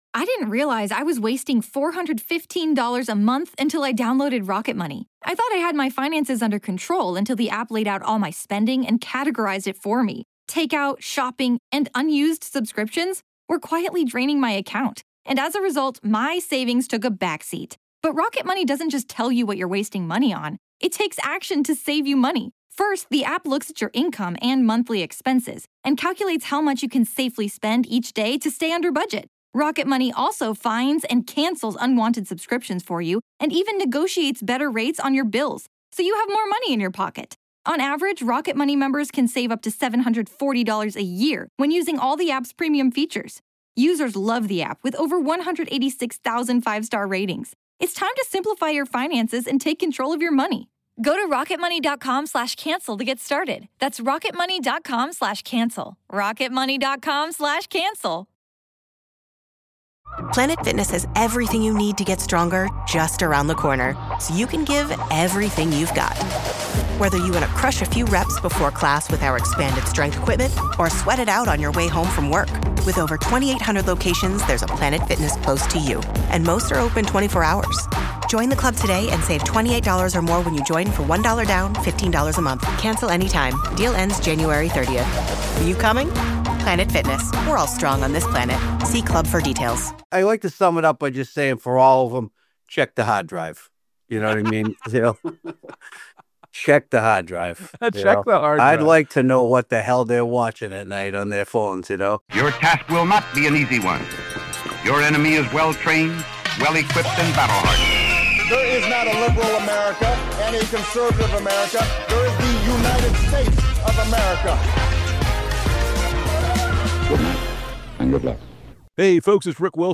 In this episode, Rick Wilson sits down with Dropkick Murphys frontman Ken Casey to unpack how Trump’s brand of fake populism conned millions of working Americans while delivering power, tax cuts, and protection to billionaires and authoritarians. Rick and Ken break down how grievance replaced policy, how MAGA culture turned resentment into a loyalty test, and why the people waving the biggest flags ended up getting sold out first.